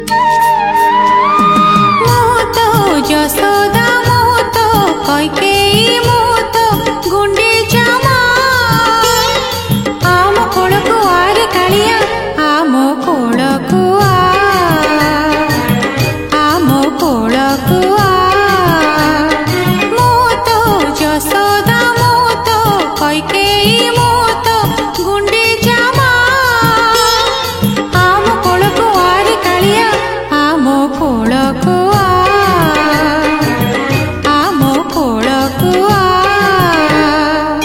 Bhajana song